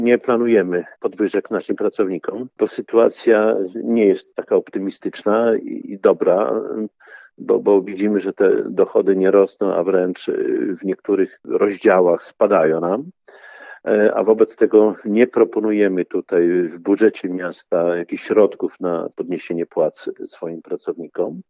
Szczegół przedstawił Czesław Renkiewicz, prezydent Suwałk.